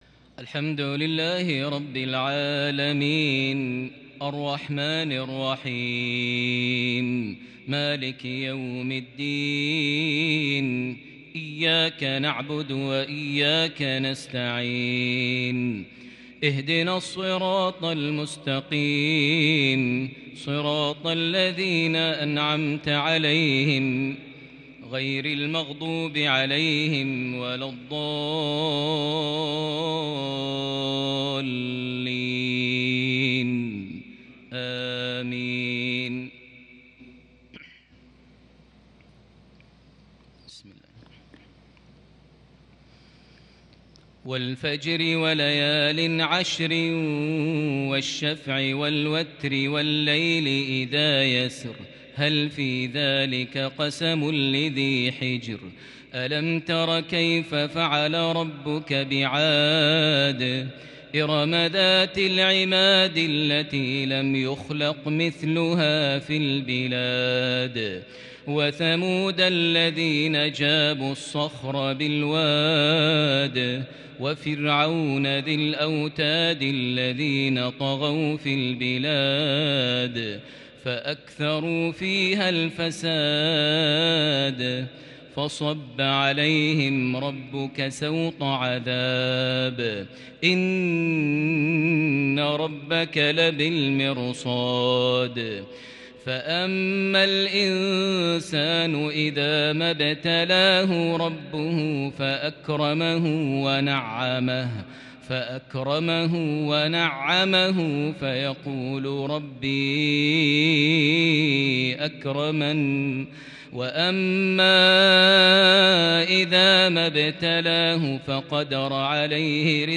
تلاوة مسترسلة من صلاة المغرب ٢٤ شوال ١٤٤١هـ لسورة الفجر > 1441 هـ > الفروض - تلاوات ماهر المعيقلي